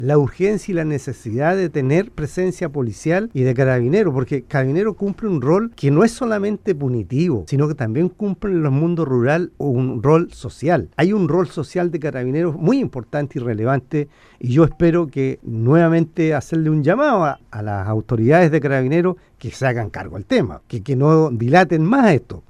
En conversación con Radio Sago, el alcalde de San Juan de la Costa, Bernardo Candia, expuso que han pasado cuatro años desde el siniestro sin novedades por parte de Carabineros.